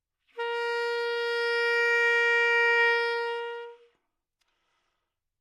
萨克斯中音单音（吹得不好） " 萨克斯中音 A4 baddynamics
描述：在巴塞罗那Universitat Pompeu Fabra音乐技术集团的goodsounds.org项目的背景下录制。单音乐器声音的Goodsound数据集。 instrument :: sax_tenornote :: A＃octave :: 4midi note :: 58microphone :: neumann U87tuning reference :: 442.0goodsoundsid :: 5090 故意扮演坏动态的一个例子